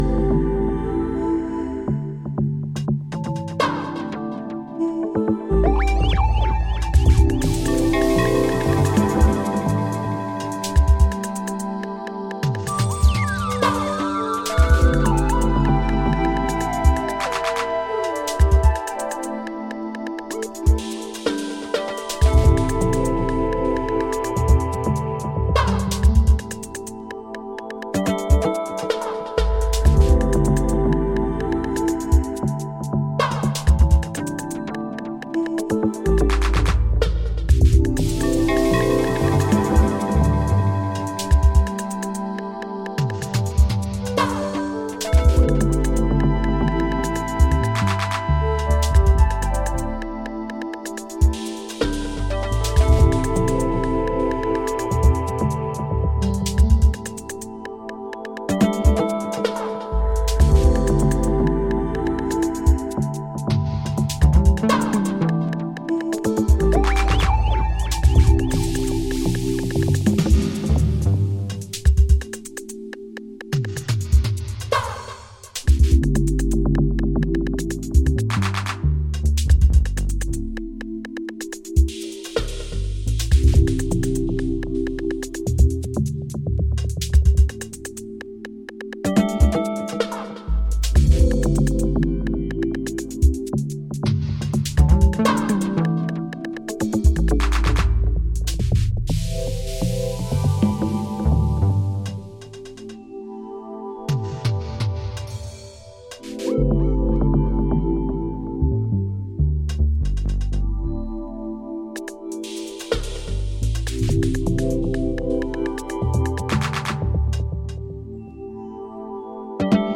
Electro Electronix House Techno